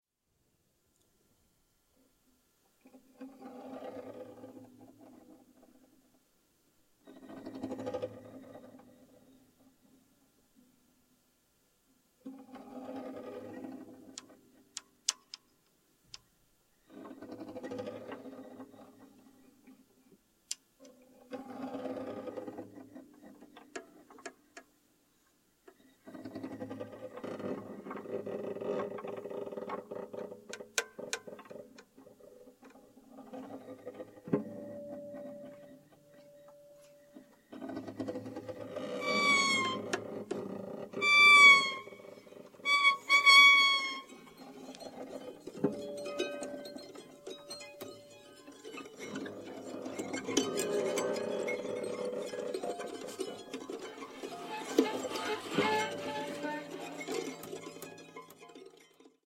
violin
mandolin
guitar